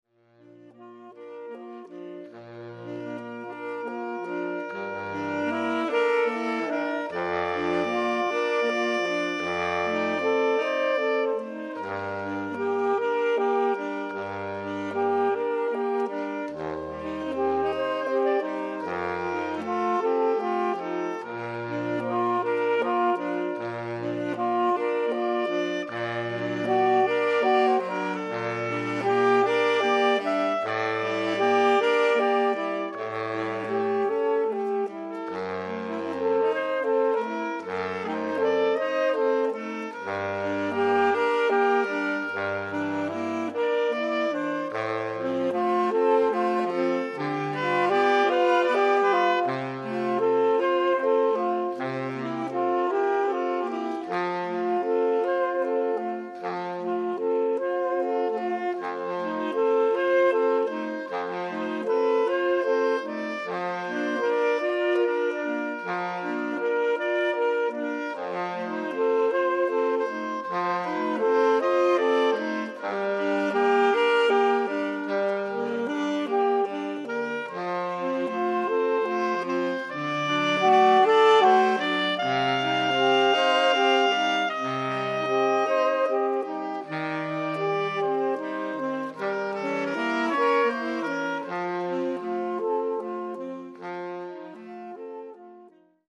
Each of the members of this group is a “triple threat”, performing on saxophone, flute and clarinet.
as a SAX QUARTET...